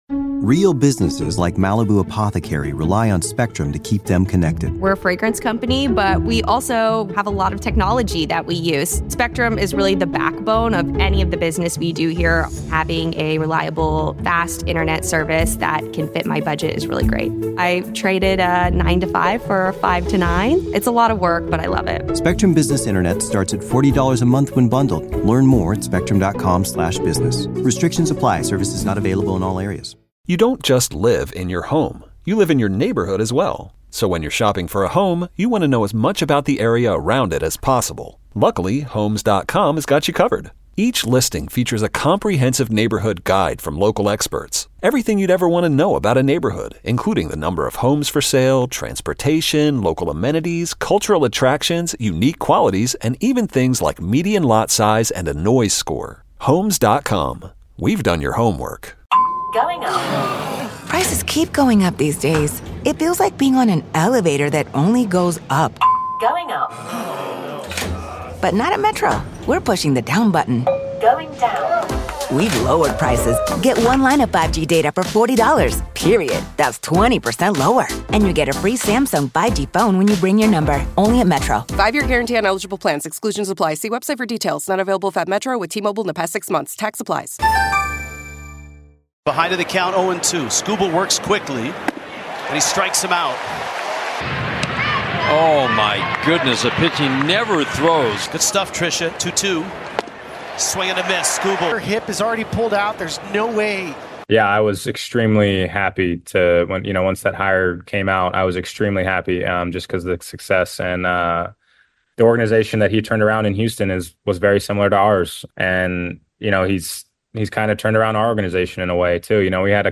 In the first hour of Inside Access, the guys discuss the Orioles-Tigers series and how good Detroit has been this season.